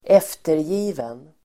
Uttal: [²'ef:terji:ven]